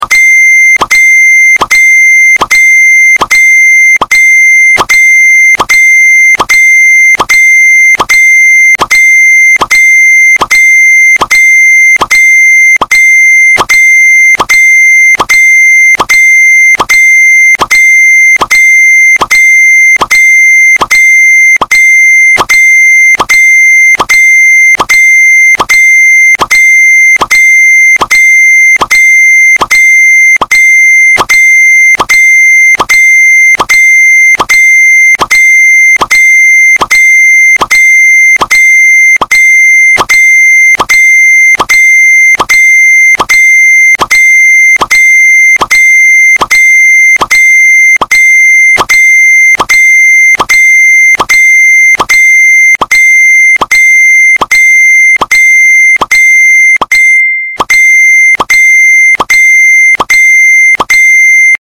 Messenger Notification Sound (Multiple)